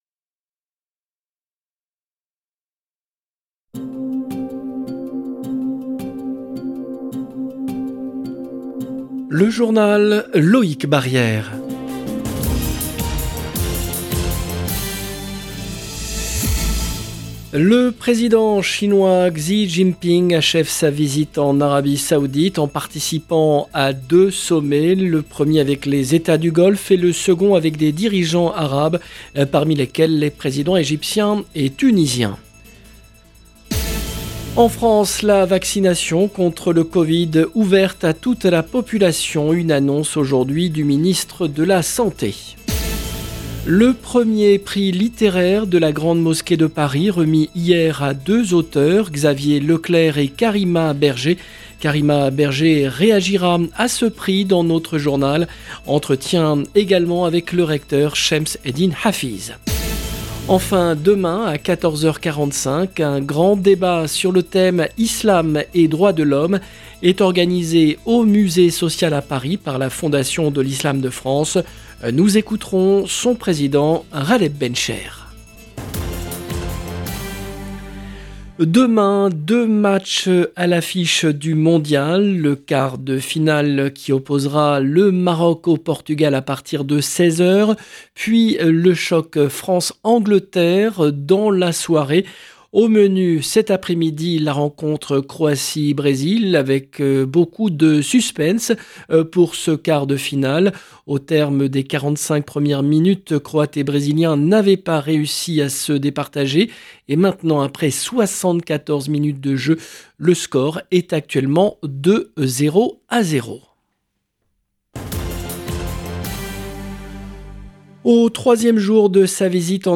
18 min 50 sec LE JOURNAL DE 17H30 EN LANGUE FRANCAISE DU 9/12/22 LB JOURNAL EN LANGUE FRANÇAISE Le président chinois XI Jinping achève sa visite en Arabie Saoudite en participant à 2 sommets.